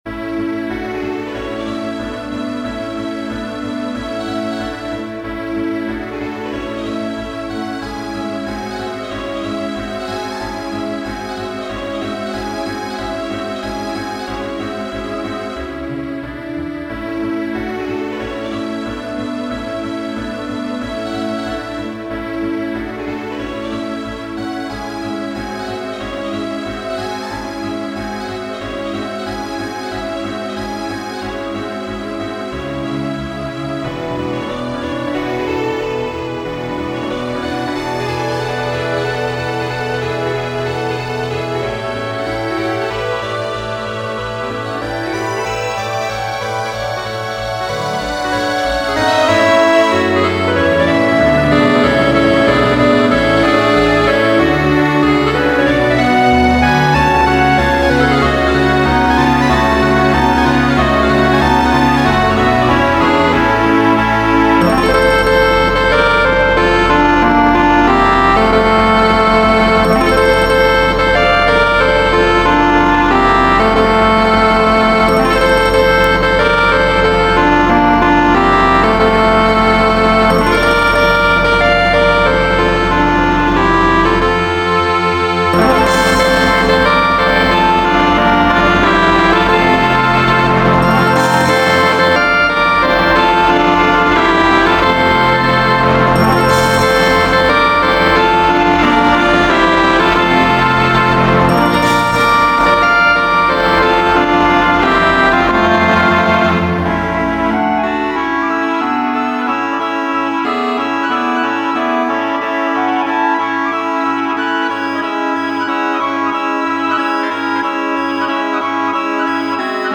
Alla marcia estas parto de la simfonia suite Karelia